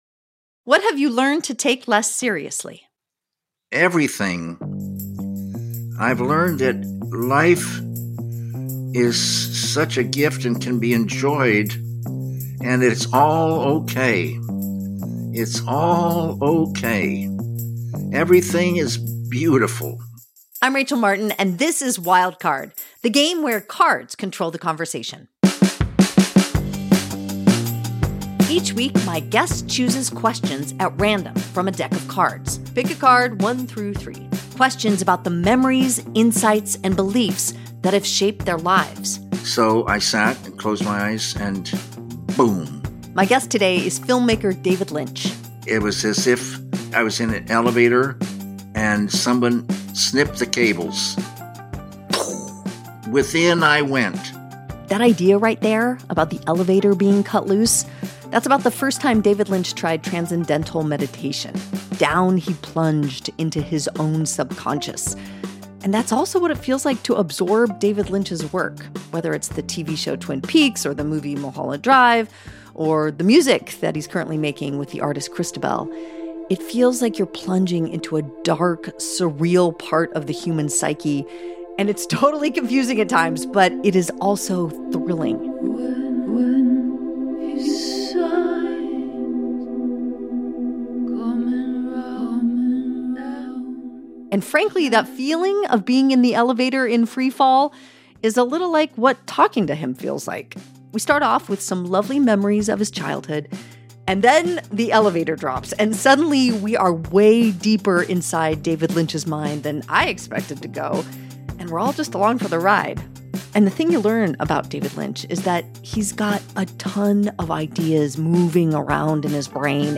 David Lynch doesn't do a lot of interviews.